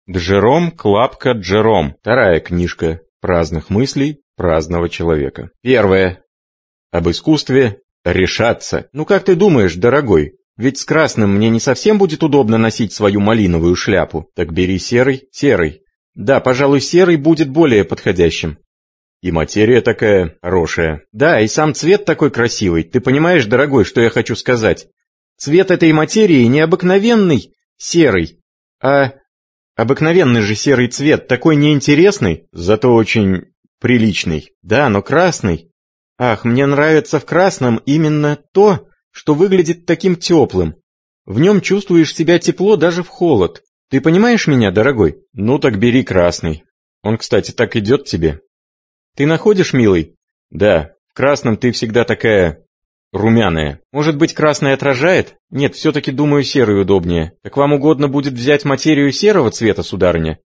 Аудиокнига Вторая книжка праздных мыслей праздного человека | Библиотека аудиокниг